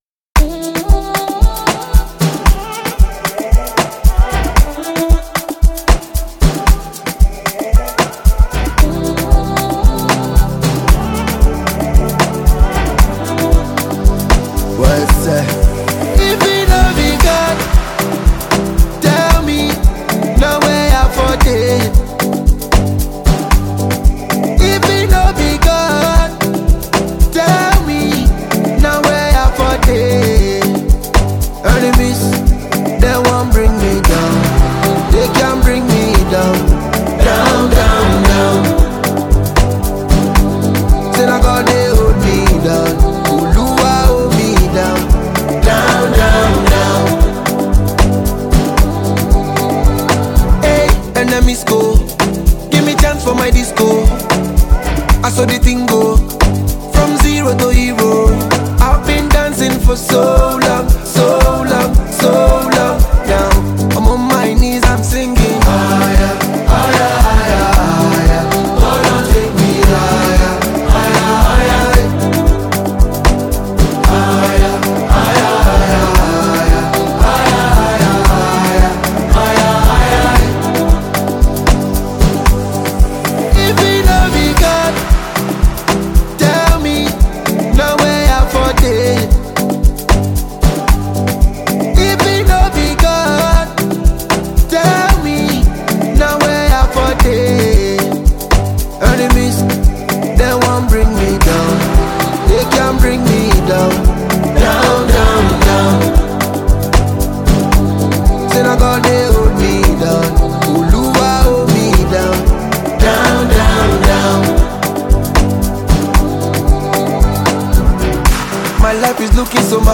Nigerian Reggae-Dancehall superstar